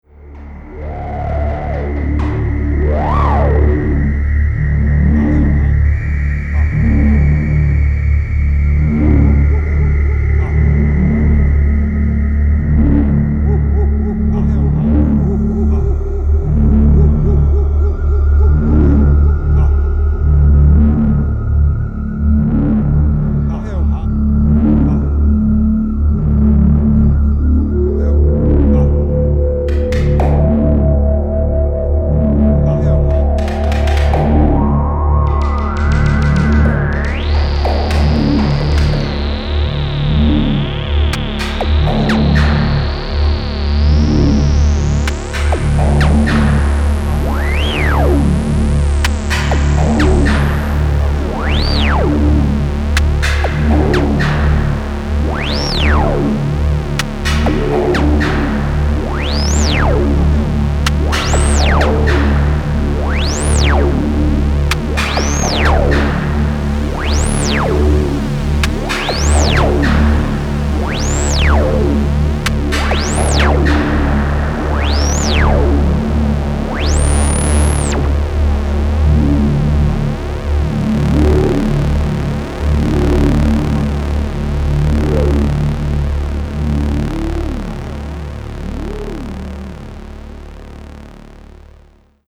Electronix Dub
Ambient